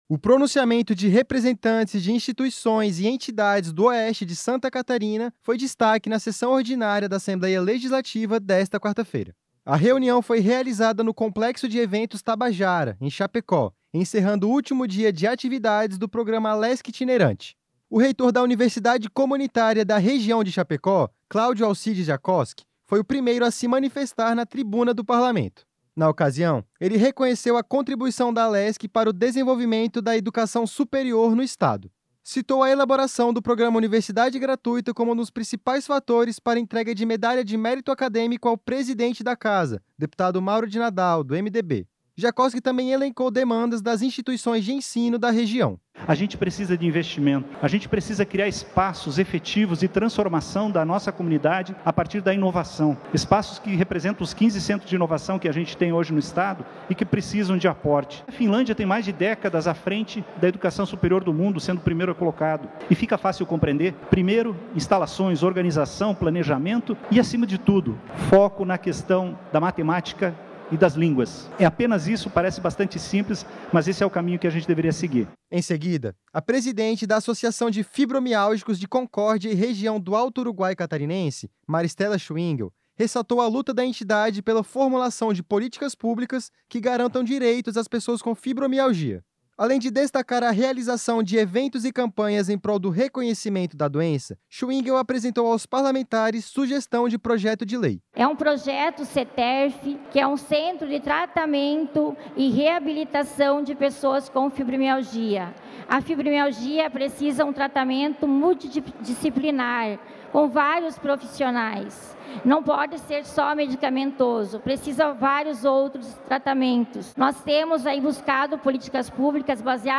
A reunião foi realizada no Complexo de Eventos Tabajara, em Chapecó, encerrando o último dia de atividades do programa Alesc Itinerante.
Entrevistas com: